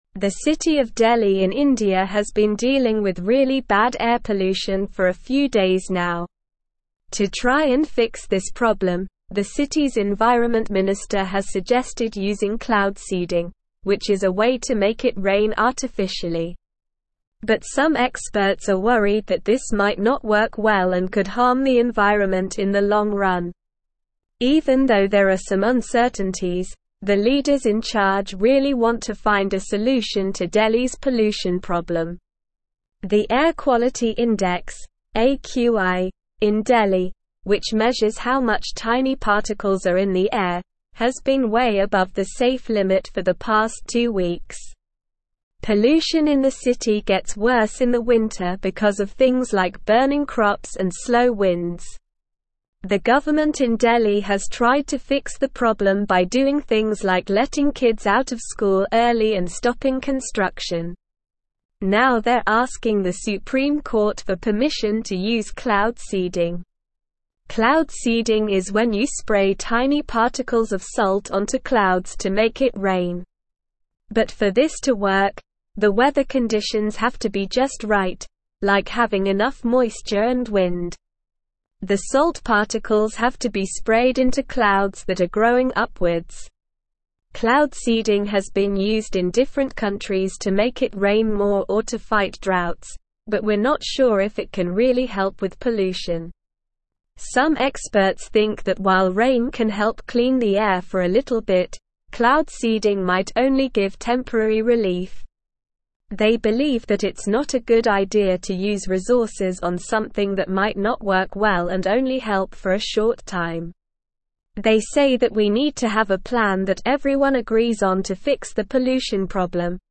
Slow
English-Newsroom-Upper-Intermediate-SLOW-Reading-Delhi-Considers-Cloud-Seeding-to-Combat-Air-Pollution.mp3